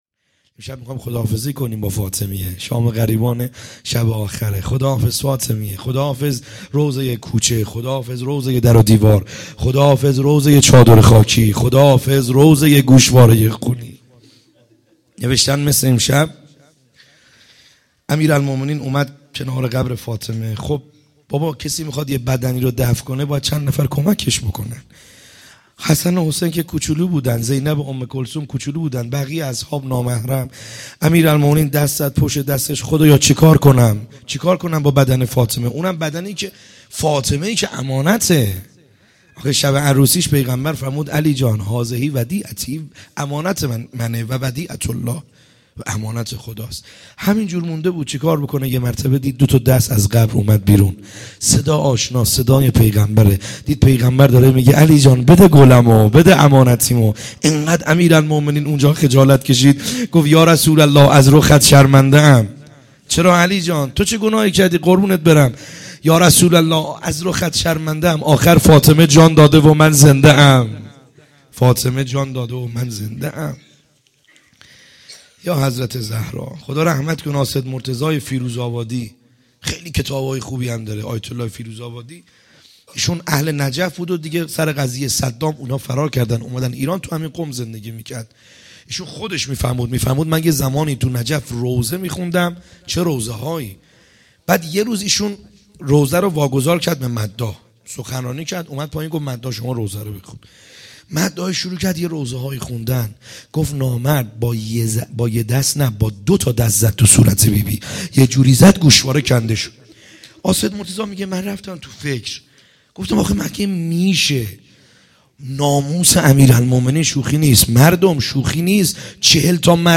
خیمه گاه - بیرق معظم محبین حضرت صاحب الزمان(عج) - روضه